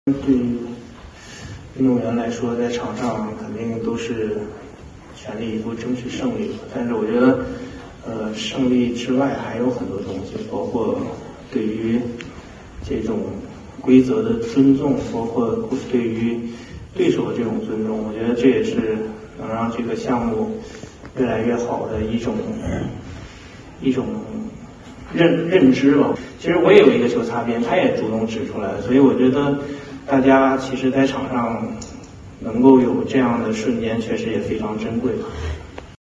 판전둥의 음성